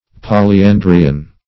Polyandrian \Pol`y*an"dri*an\, a.